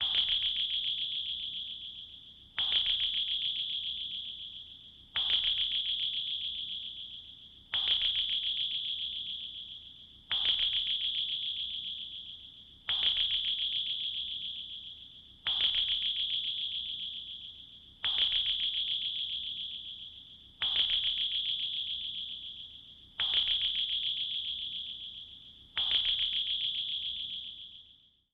Звуки сонара
Звук гидролокатора подводной лодки